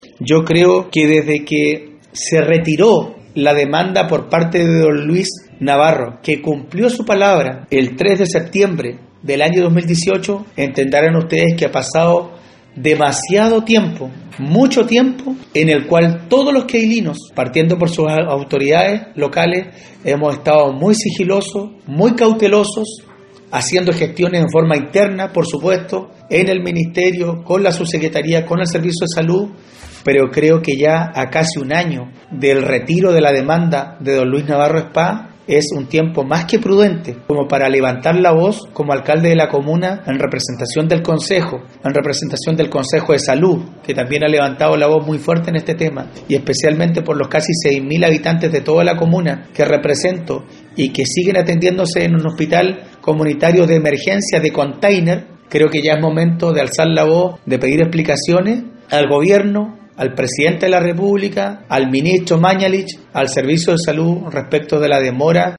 Vargas agrego que es un tiempo mas que prudente para levantar la voz, y exigirá explicaciones en quien estime conveniente que las entregue, y si es necesario, a nivel de Ministerio en la persona del Ministro Mañalich.